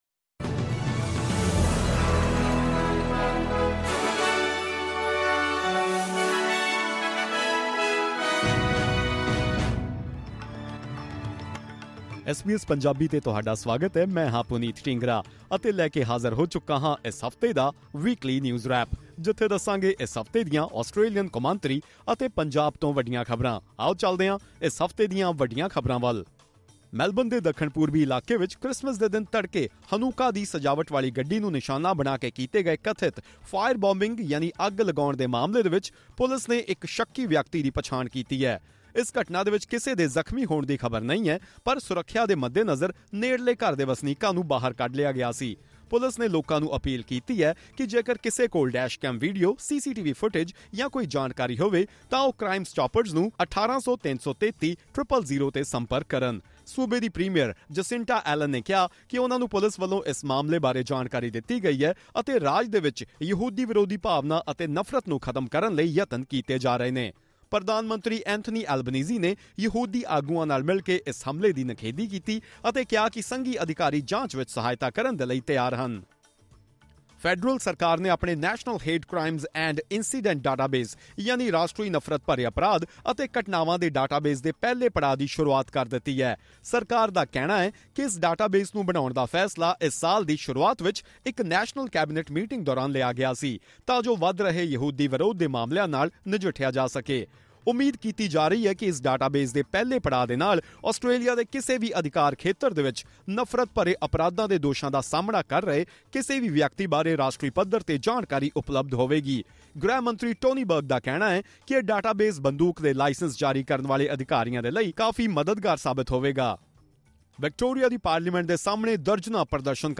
Top news updates of the week in Punjabi.